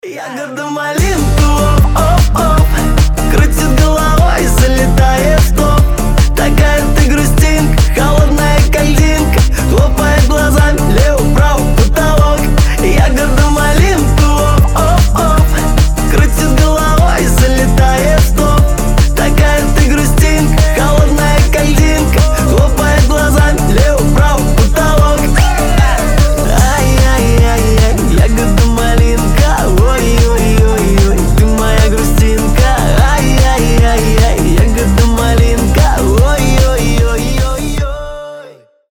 • Качество: 320, Stereo
забавные
веселые